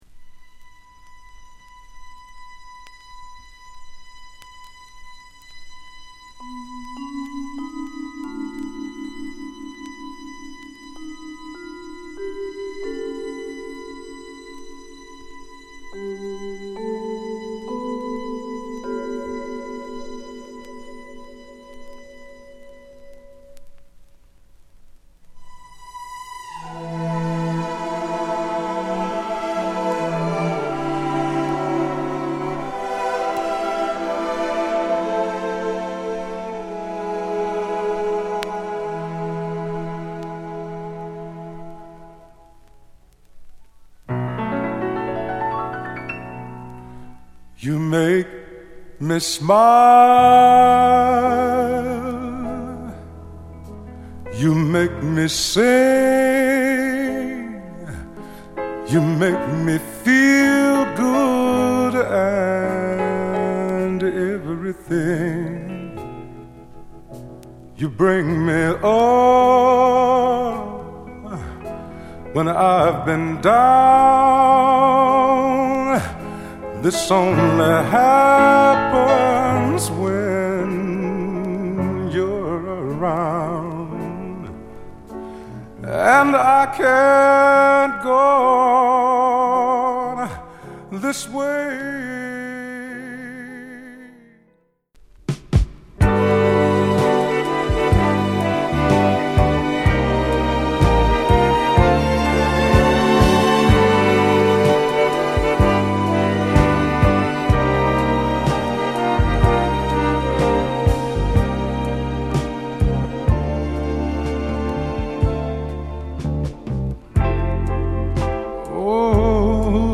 スタンダード・ヴォーカル集の様な渋い１枚です。